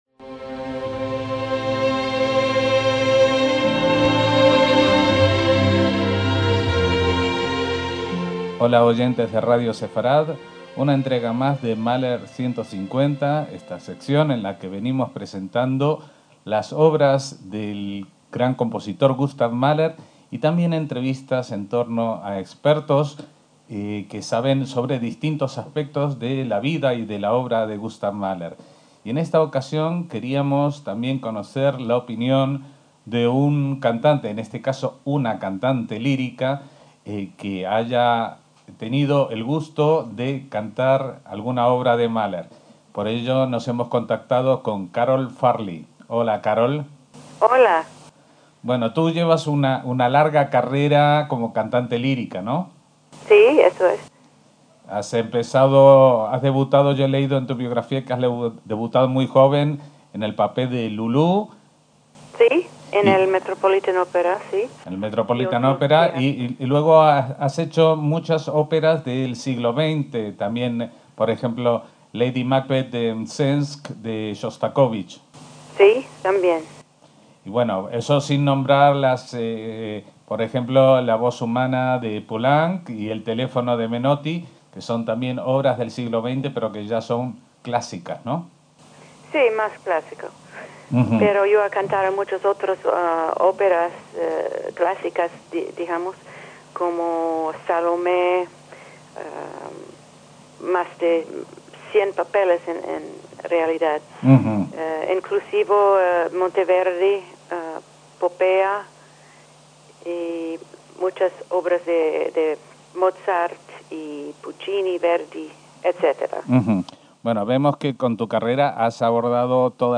La belleza de la línea vocal, con la cantante Carole Farley